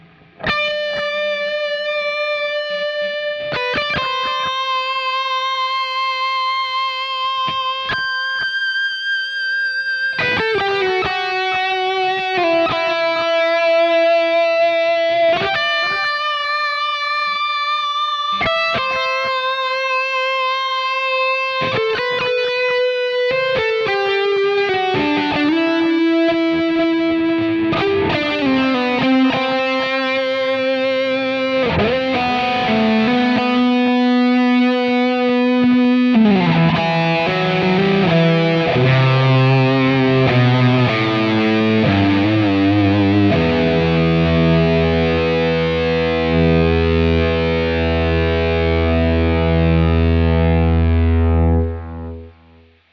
LeadDrivebreaker (4x12 V30s) 1.01 MB (0:52) no settings yet
finally got around to testing out some real pedals with my podxt. got some really pleasant results with my Sobbat DriveBreaker I... basically a souped-up handmade japanese tubescreamer... same chip, but a lot more distortion, as well as better clean boost capabilities and separate bass and treble controls.
for this sound, i used the highway 100 amp, and recorded with three separate cabs... the 4x12 v30, 4x12 green 25, and 1x12 blackface. ibanez 540, bridge humbucker. i'll post settings later, but they won't do you much good without the pedal... pedal settings are: